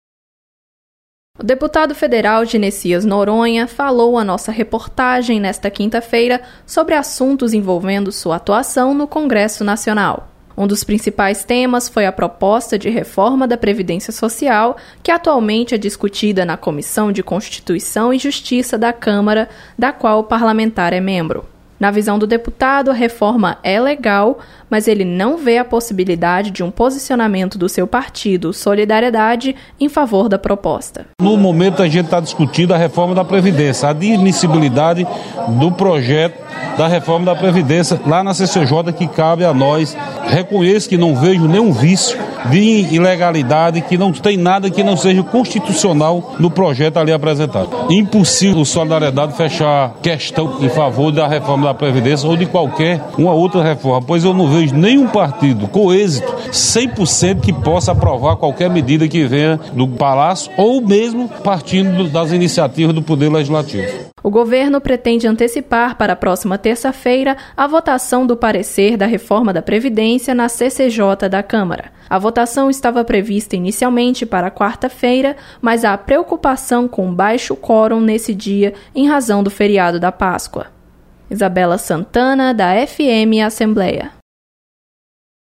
Deputado federal comenta legalidade da reforma da previdência, em discussão na CCJ da Câmara.